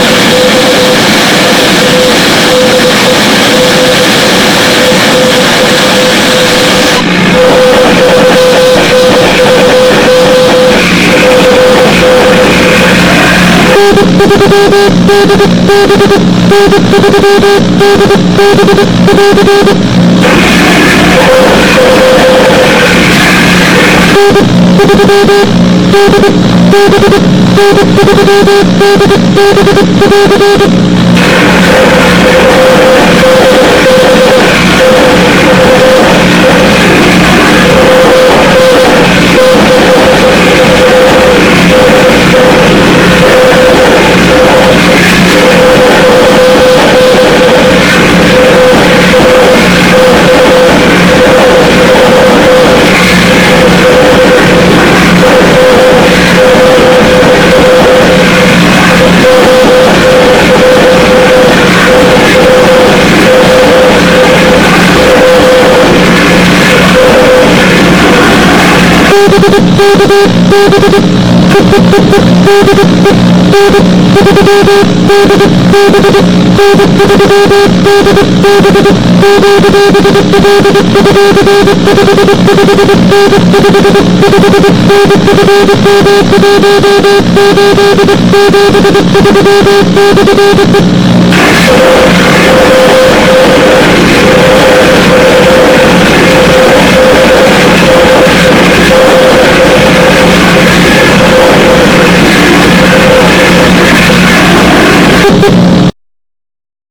The recordings themselves were made with an old cassette tape recorder located below one of the two outdated Labtec sound-blaster speakers I use as externals.
RA ST Another example of my late season QRN